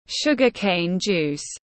Nước mía tiếng anh gọi là sugar cane juice, phiên âm tiếng anh đọc là /ˈʃʊɡ.ə ˌkeɪn ˌdʒuːs/